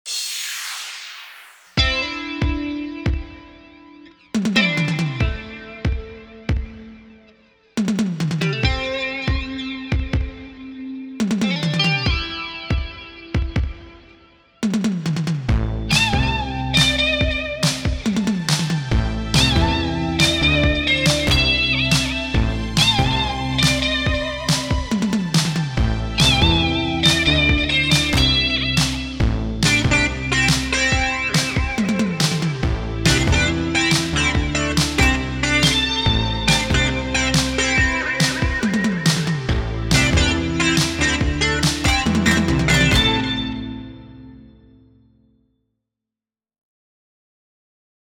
Home > Music > Rnb > Bright > Smooth > Medium